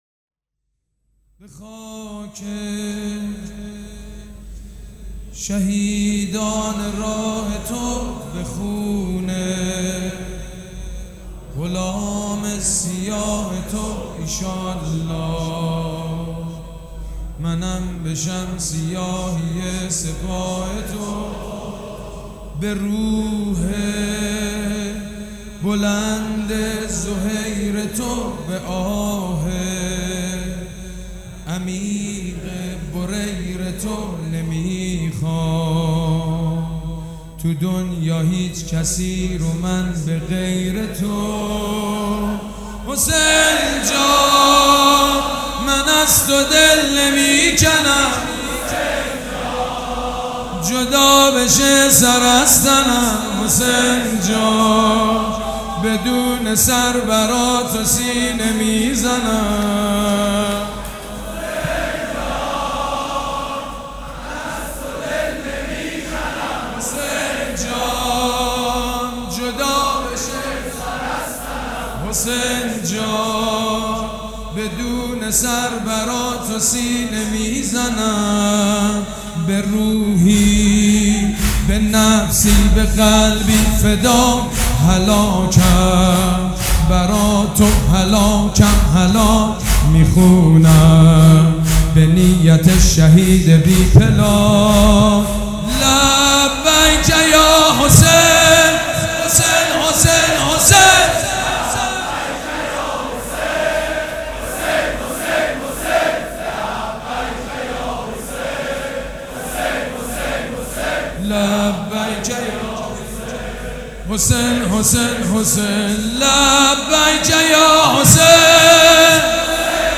مراسم عزاداری شب هفتم